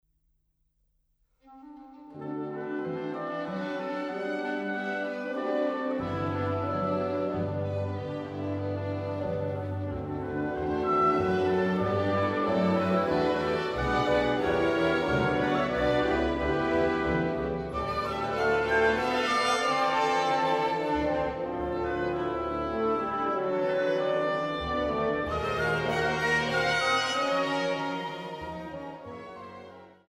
Orchesterwerke verfemter Komponisten